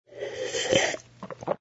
sfx_slurp_glass01.wav